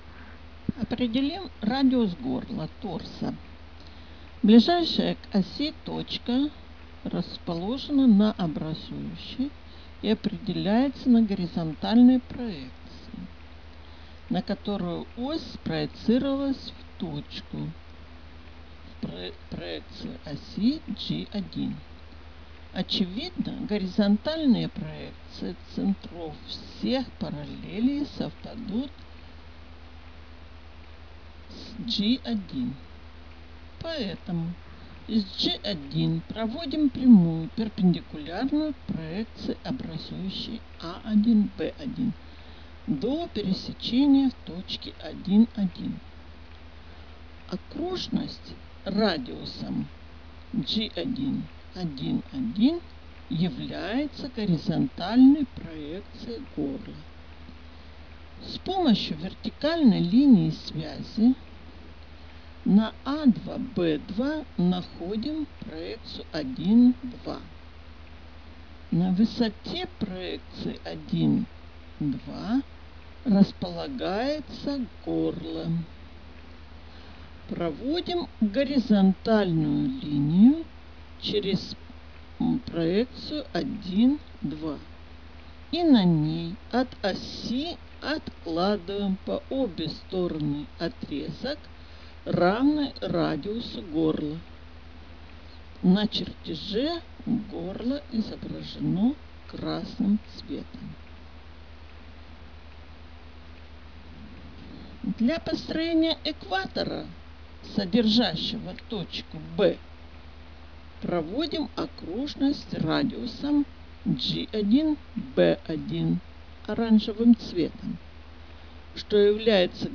Аудио-комментарии